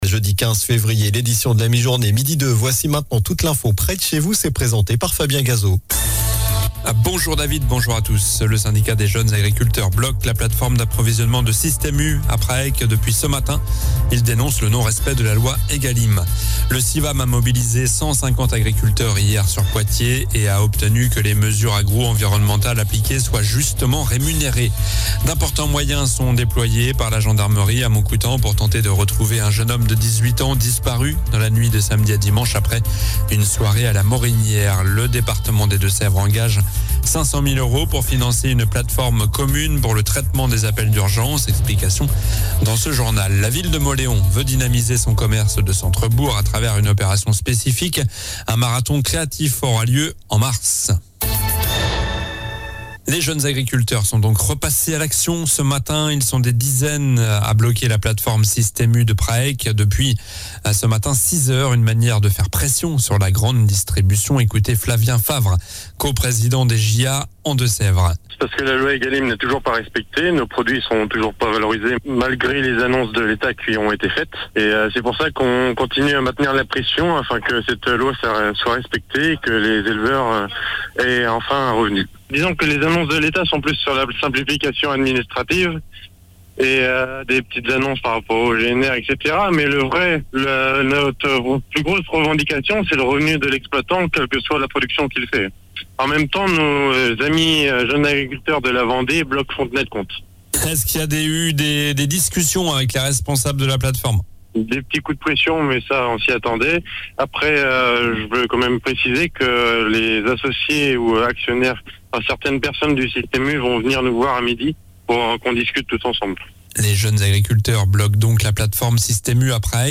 Journal du jeudi 15 février (midi)